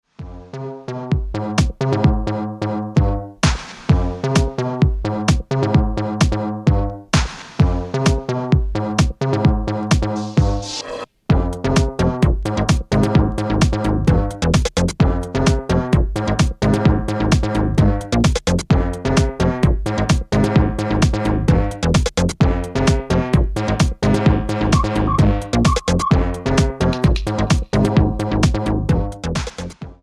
One of the biig dance records of 2007.